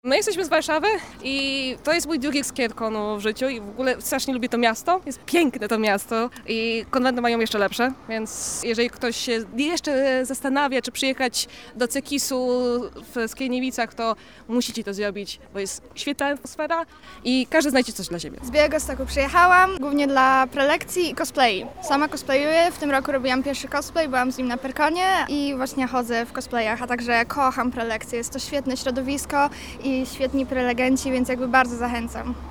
Posłuchaj uczestników: